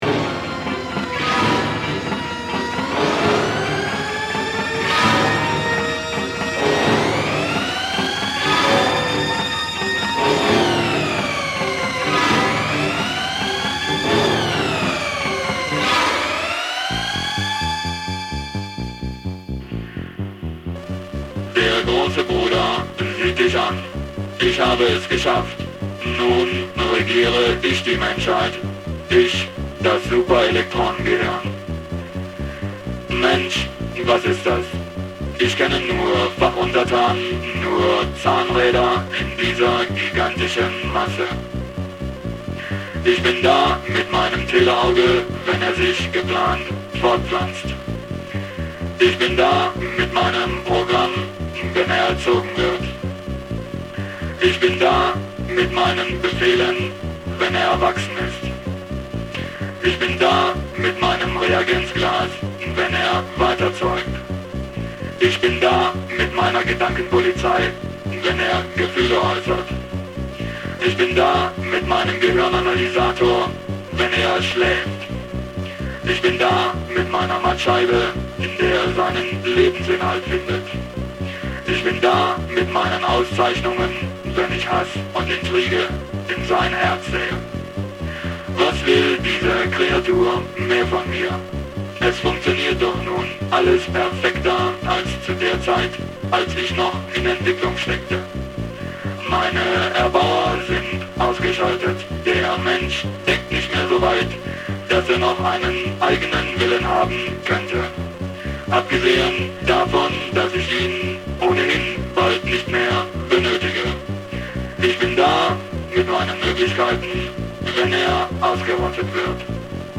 genre = レコメン・アヴァンポップ Avan-Pop, RIO